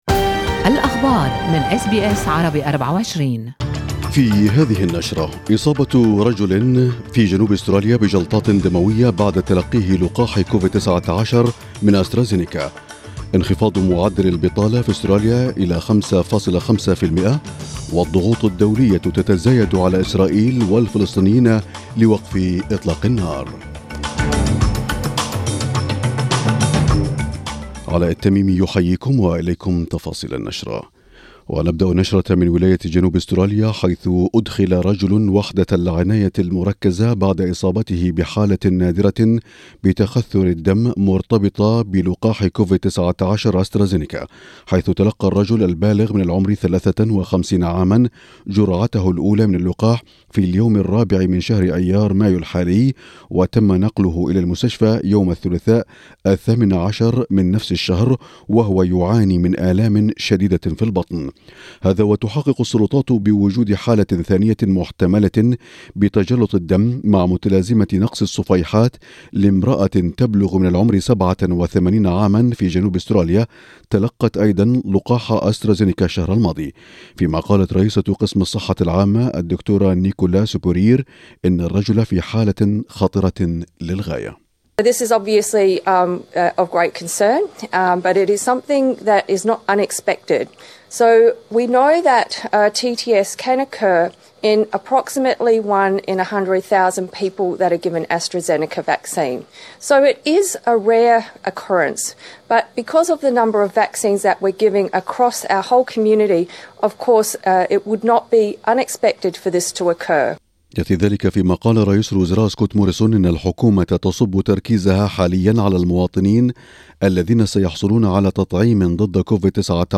نشرة أخبارالمساء 20/5/2021